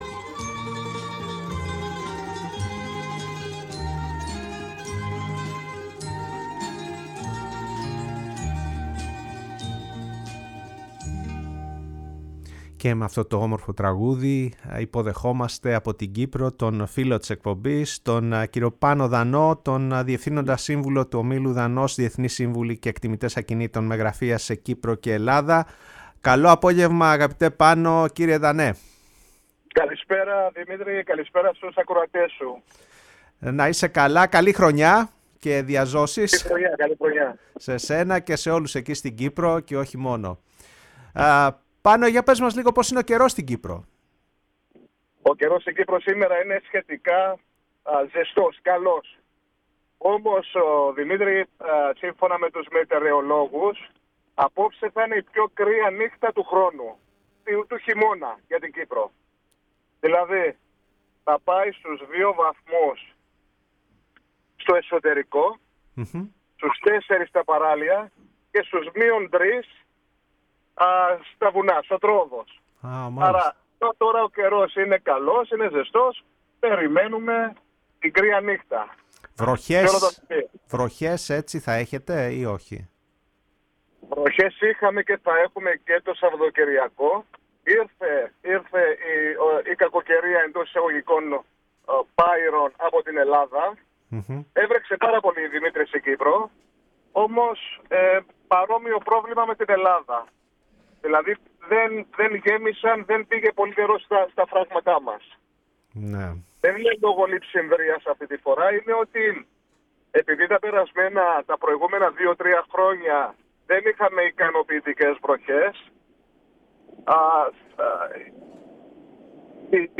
Η ΦΩΝΗ ΤΗΣ ΕΛΛΑΔΑΣ Η Παγκοσμια Φωνη μας ΣΥΝΕΝΤΕΥΞΕΙΣ Συνεντεύξεις αγορα ακινητων Γιωργος Βασιλειου ΕΕ ΕΛΛΗΝΟΚΥΠΡΙΟΙ ΙΡΑΝ ΚΥΠΡΟΣ ΝΕΡΟ ΣΚΑΝΔΑΛΟ